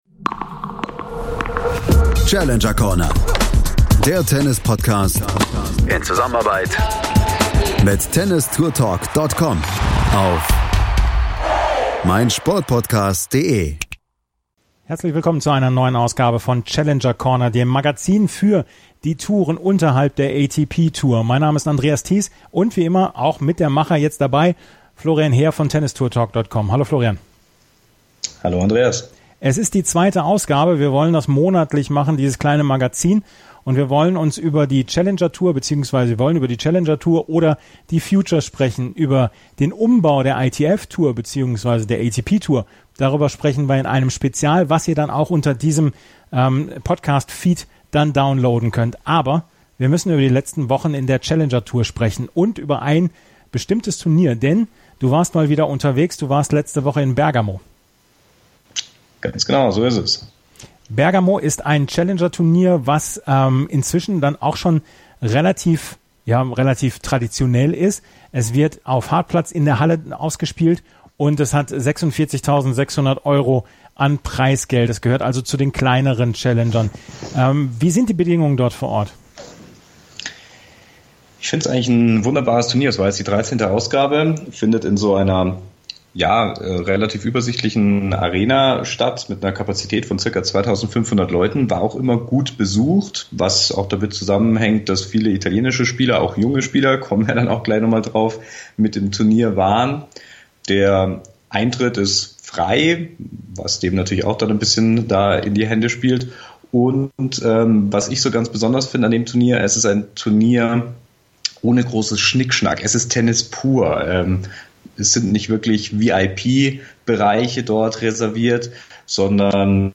ausführlichen Interview